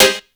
35SYNT02  -R.wav